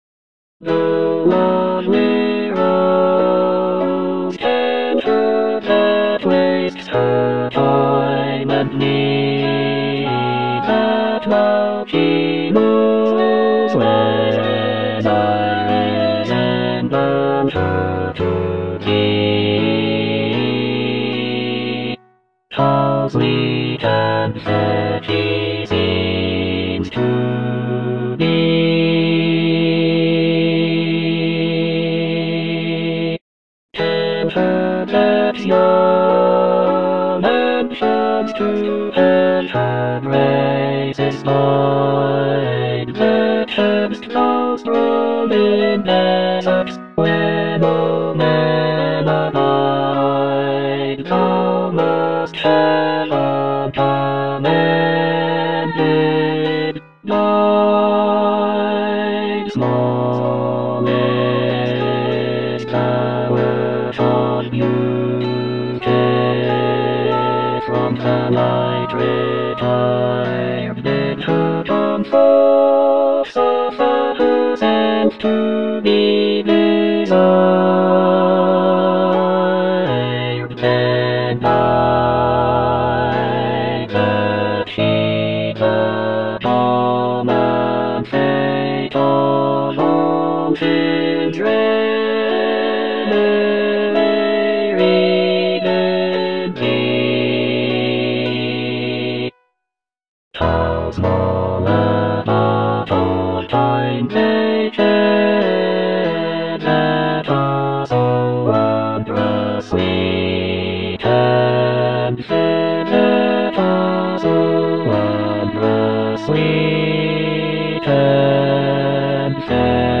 Bass I (Emphasised voice and other voices)